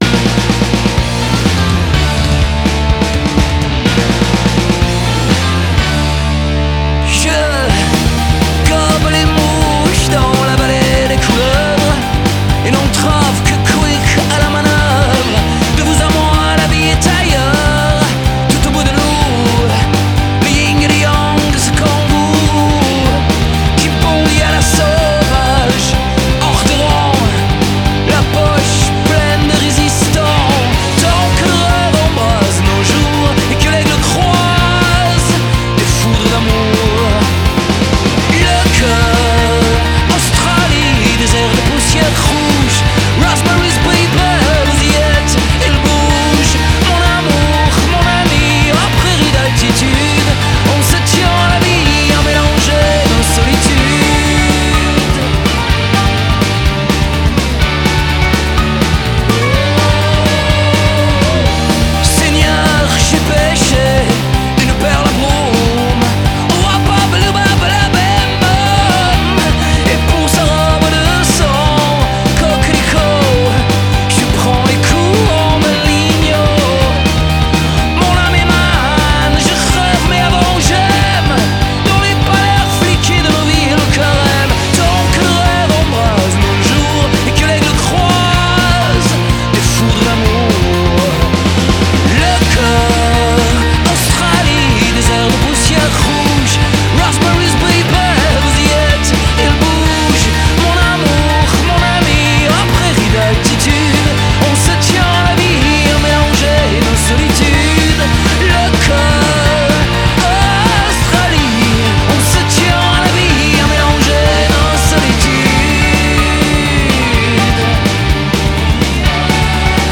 Du bon vieux rock n’ roll qui tache.